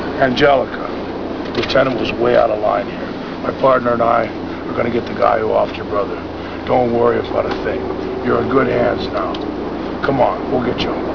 complete with monotone delivery of his lines).